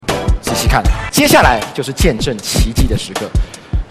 刘谦见证奇迹的时刻音效_人物音效音效配乐_免费素材下载_提案神器